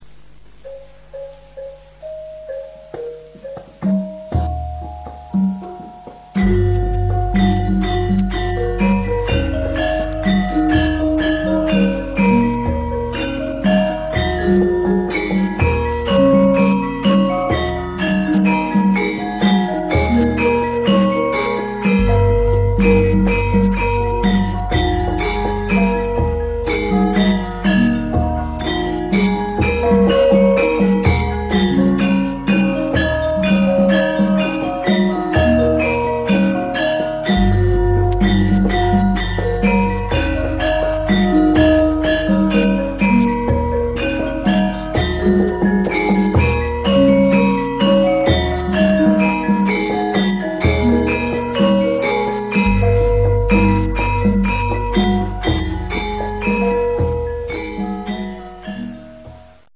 Gamelan Music
Gendhing from Central Java, 118k
gamalan.ra